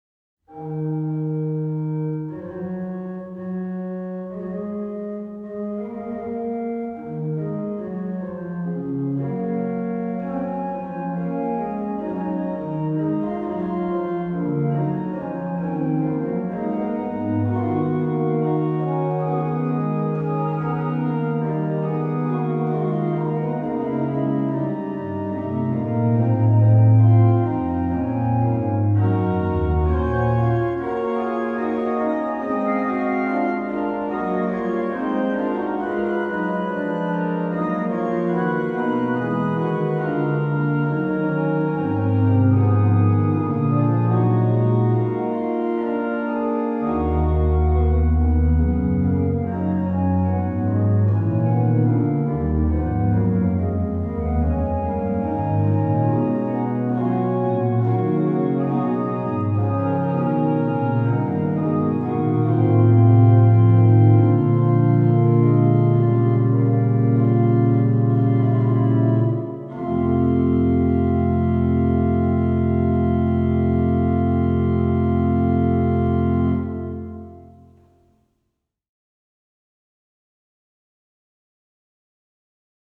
Subtitle   Fughetta; manualiter
Registration   Pr8, Rfl4, Nas3